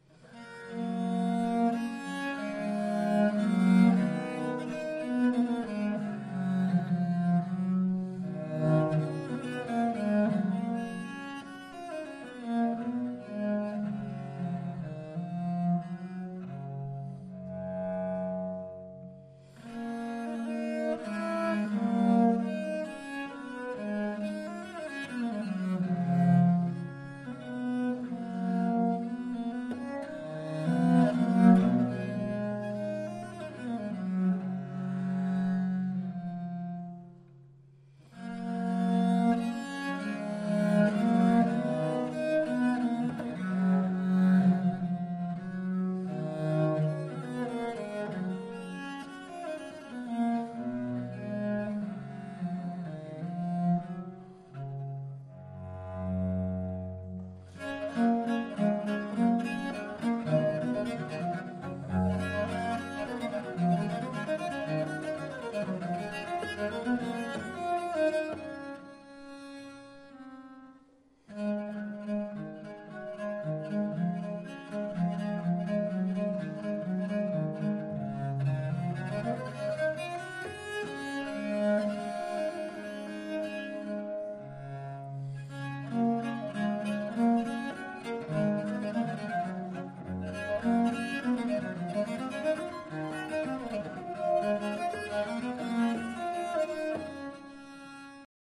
La musique baroque vivante !
Pièce de Abel pour Viole de Gambe
Enregistré à l'église d'Arberats (64120) le 24 Juillet 2012